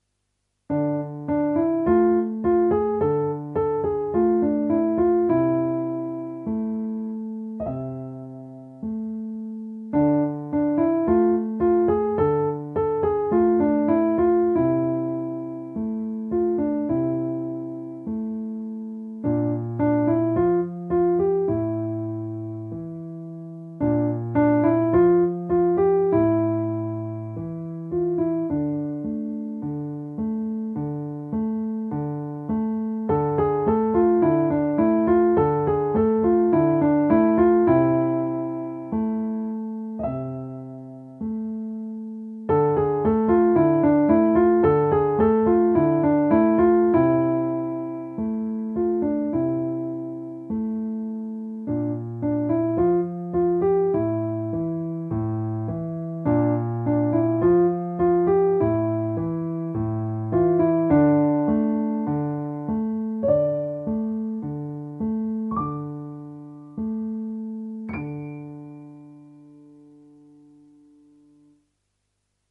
Besetzung: Klavier
Untertitel: 38 sehr leichte Klavierstücke mit Achteln